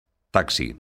taxi_son.mp3